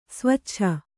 ♪ svaccha